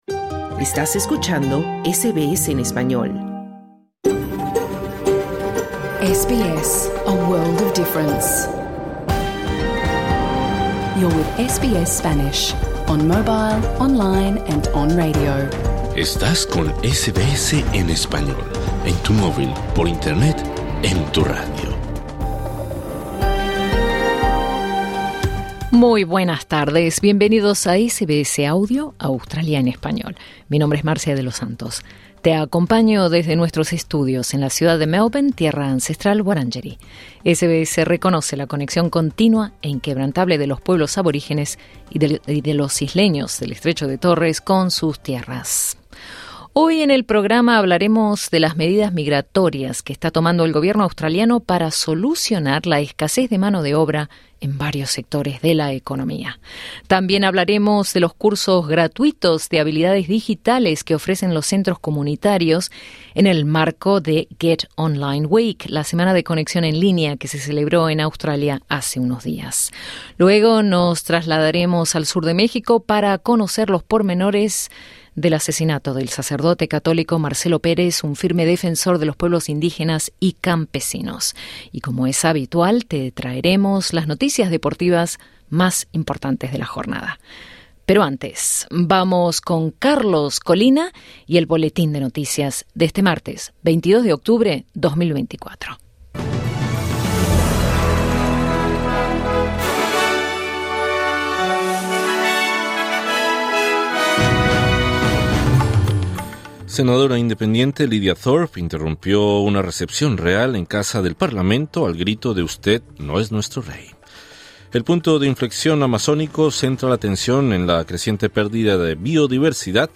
Programa en vivo | SBS Spanish | 22 octubre 2024